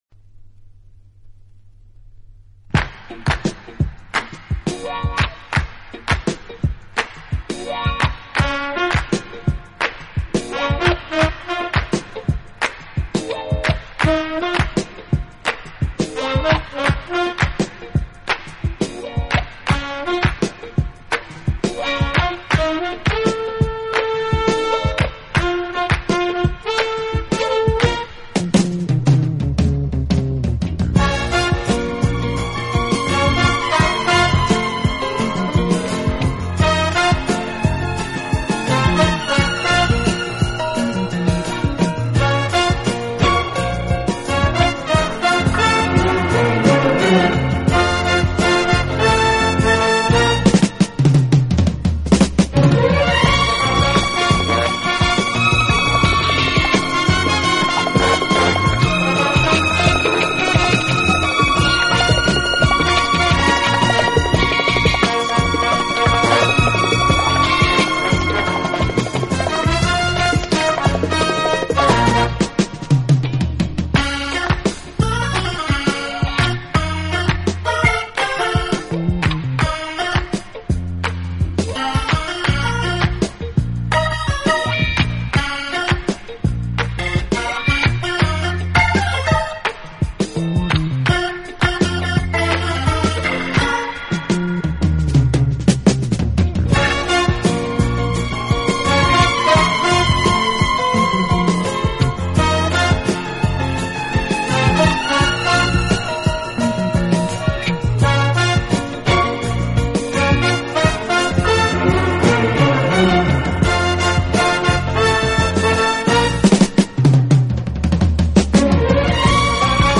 好处的管乐组合，给人以美不胜收之感。